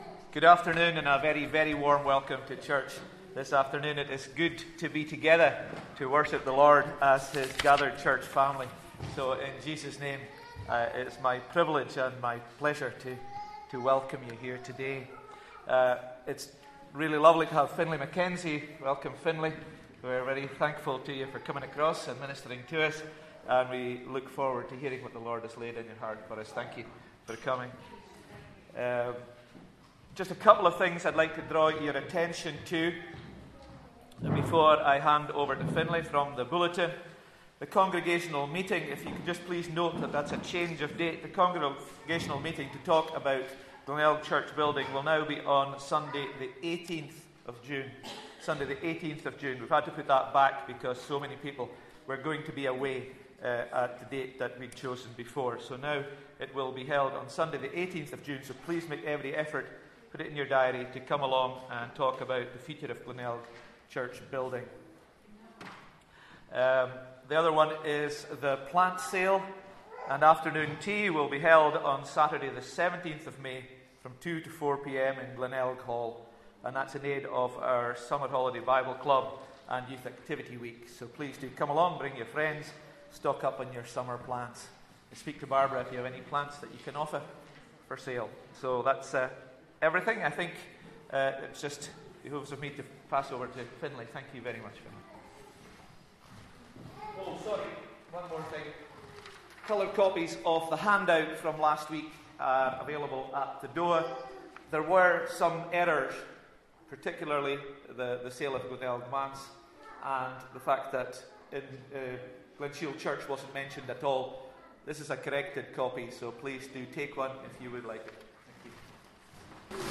12-Noon-Service-.mp3